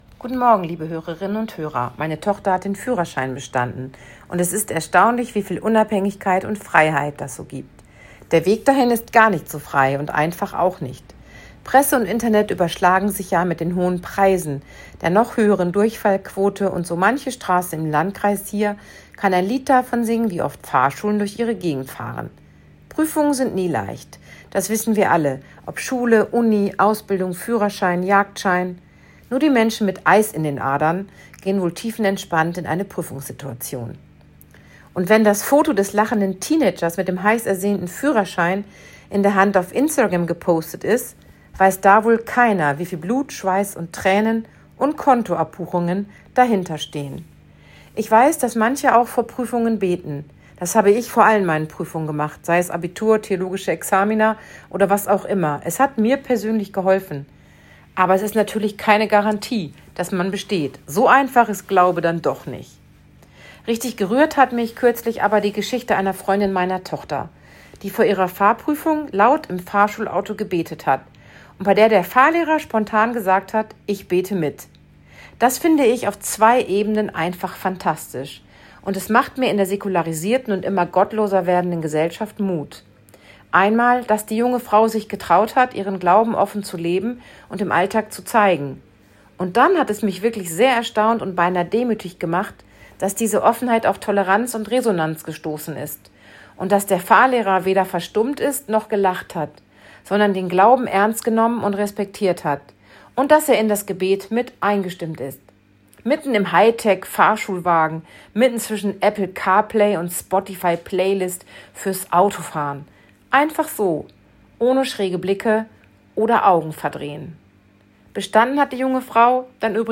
Radioandacht vom 18. September